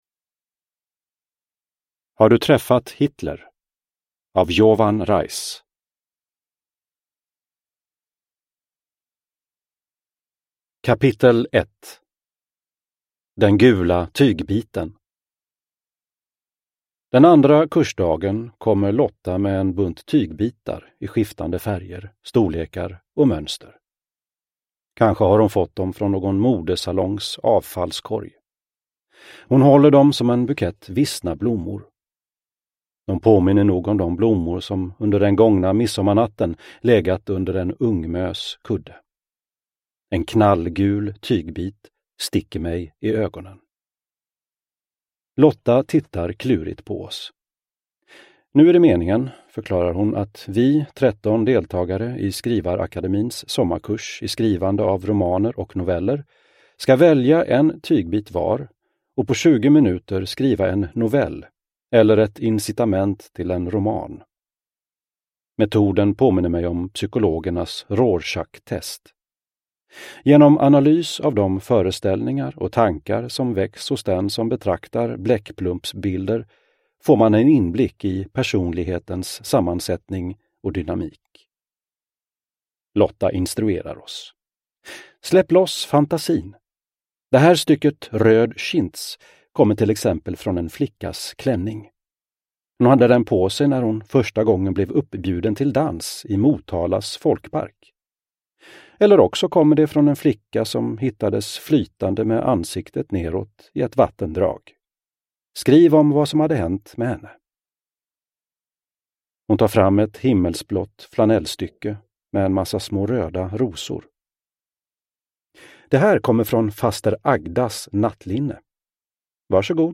Har du träffat Hitler? : berättelser om judehat och rasism – Ljudbok – Laddas ner